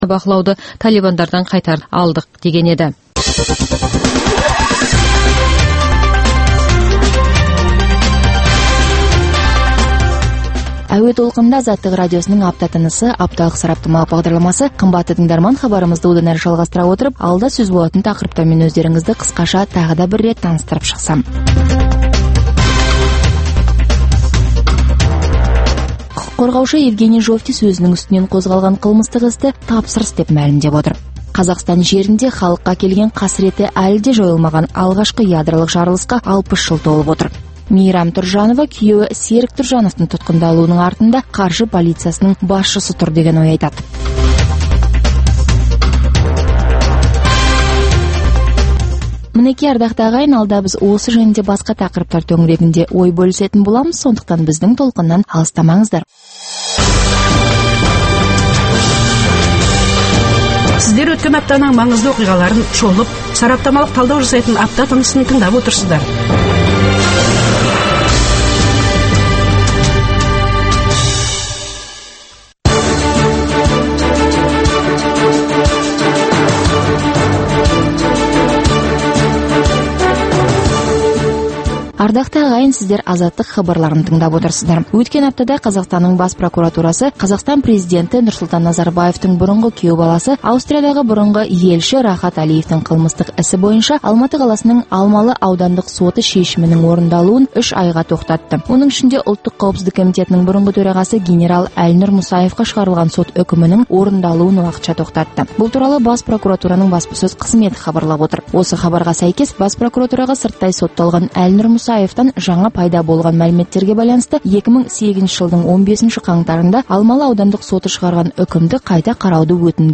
Апта ішінде орын алған елеулі оқиғалар мен өзгеріс, құбылыстар турасында сарапшылар талқылаулары, оқиға ортасынан алынған репортаждардан кейін түйіндеме, пікірталас, қазақстандық және халықаралық талдаушылар пікірі, экономикалық сараптамалар.